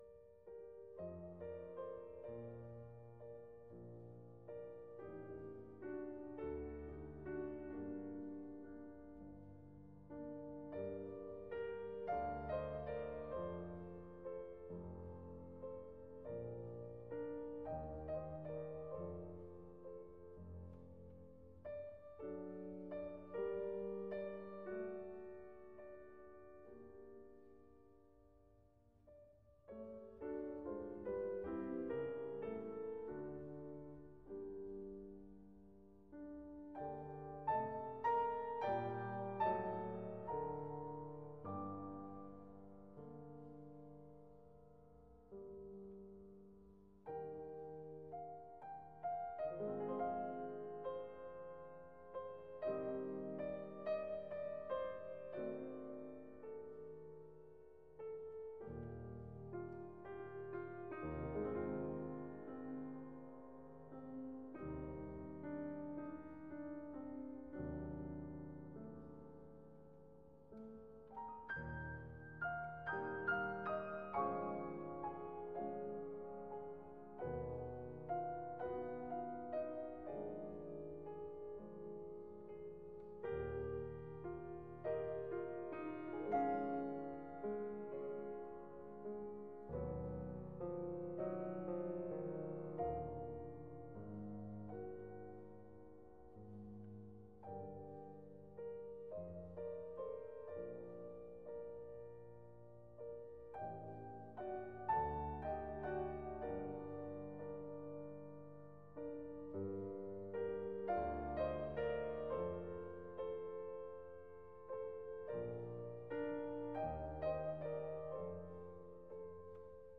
Works for piano - Dream Children Op. 43 : 1 Andante (1902) - 2,31 Mo - 2 mn 39 :